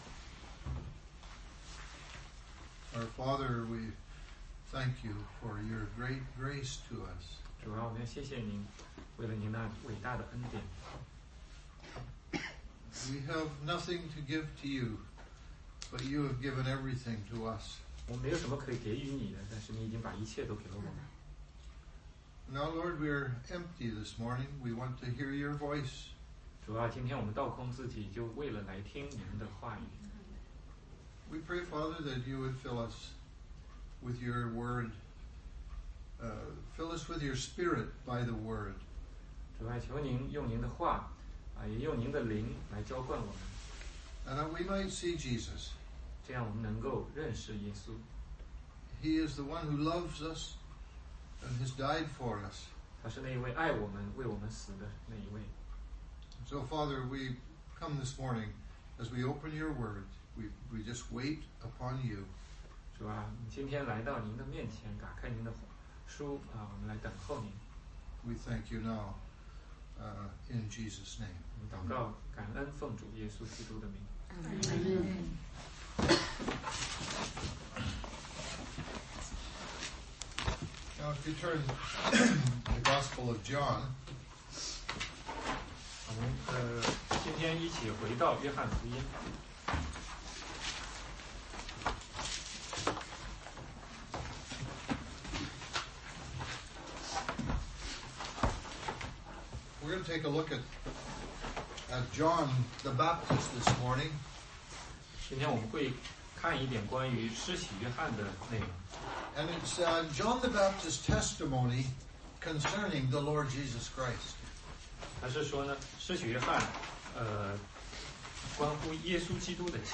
16街讲道录音 - 约翰福音1 19-34